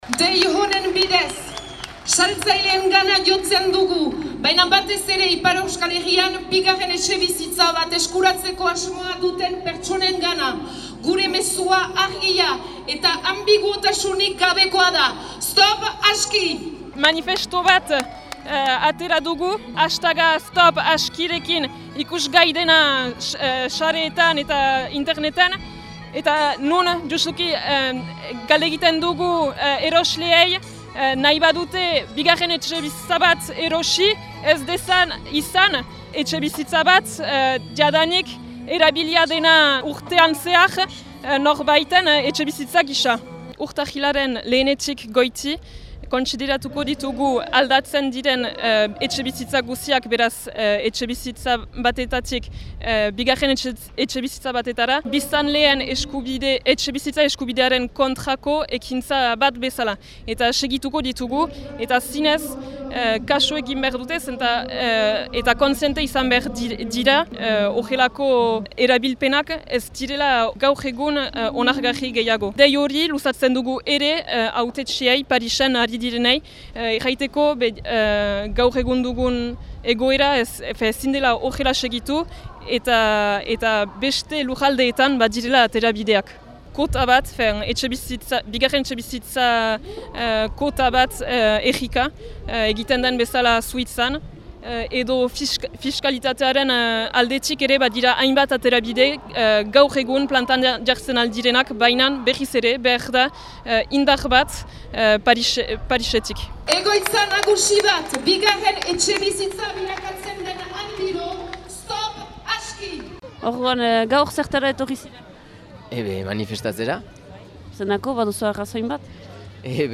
gazteen lekukotasun eta bizipenak bildu dituzu.